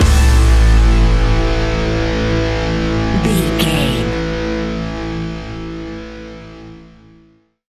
Ionian/Major
hard rock
instrumentals